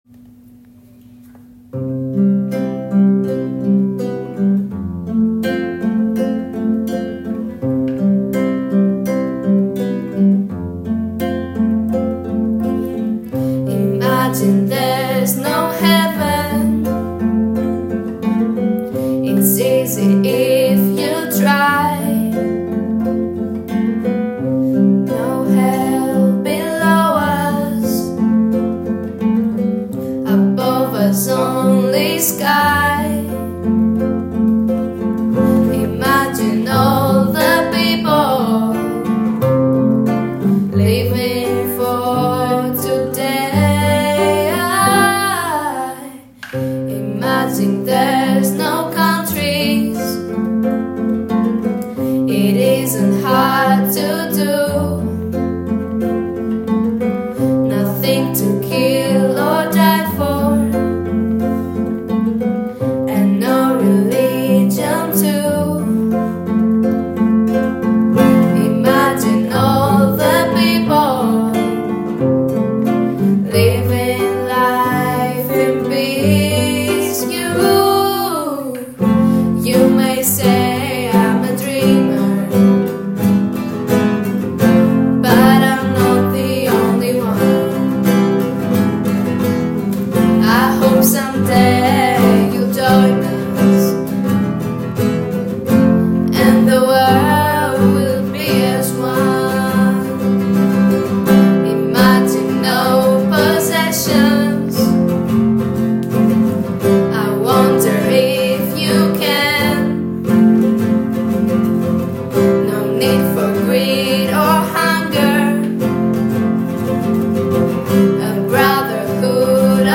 Συμμετοχή στον εορτασμό της Ημέρας της Ευρώπης στην εκδήλωση του Europe Direct – 2ο ΠΕΙΡΑΜΑΤΙΚΟ ΓΕΛ ΛΕΥΚΑΔΑΣ
• Τραγούδησαν το “Imagine” του John Lennon, στέλνοντας ένα δυνατό μήνυμα ειρήνης και ενότητας — ένα μήνυμα που ακούστηκε ζωντανά και συγκίνησε.